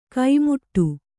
♪ kaimuṭṭu